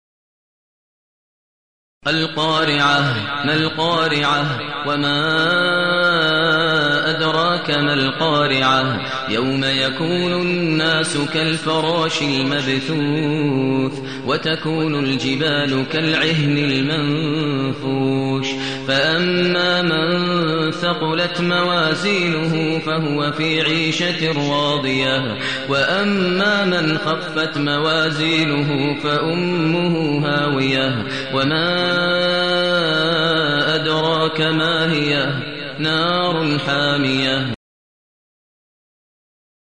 المكان: المسجد الحرام الشيخ: فضيلة الشيخ ماهر المعيقلي فضيلة الشيخ ماهر المعيقلي القارعة